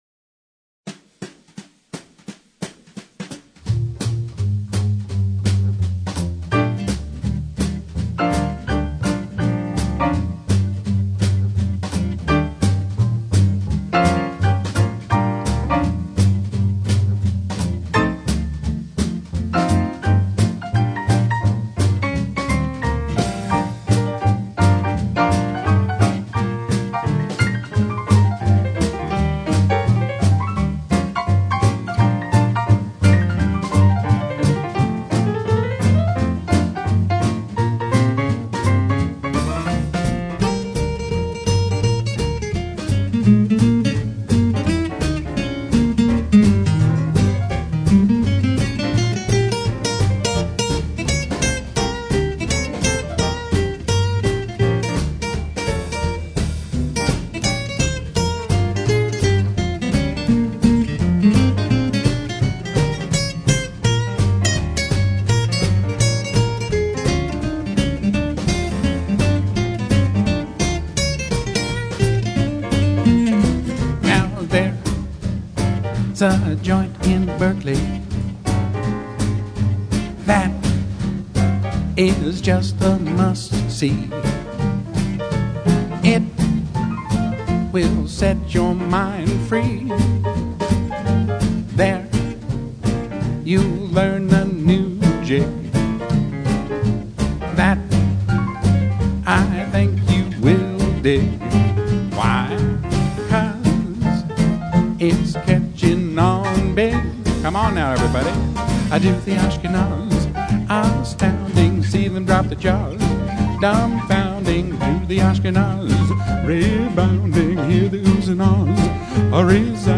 1920s Syncopated Jazz and 1930s-Big Band Swing styles